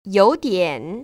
[yǒu diǎn]
요 디엔